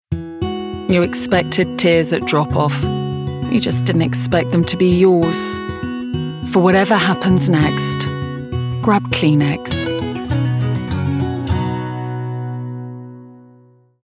RP
Female
Assured
Bright
Dry
KLEENEX COMMERCIAL